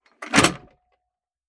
Ammo Crate Close 003.wav